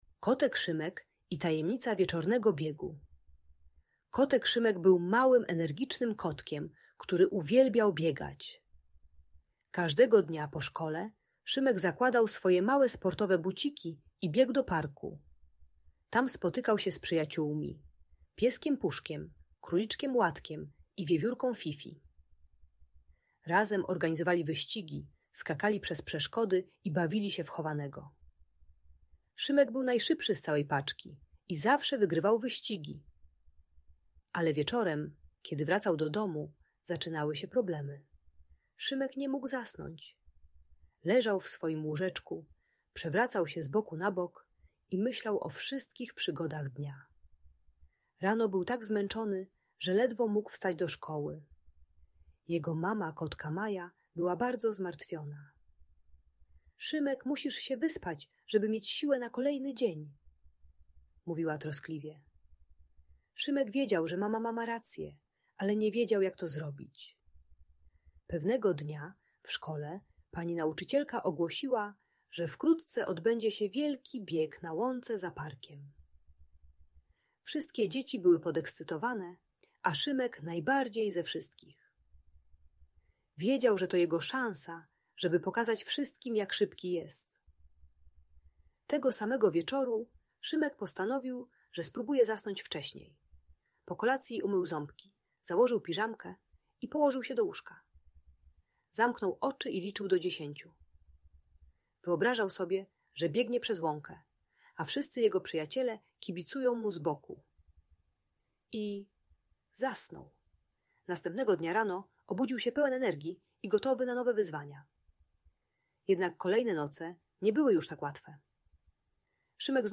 Kotek Szymek i Tajemnica Wieczornego Biegu - Audiobajka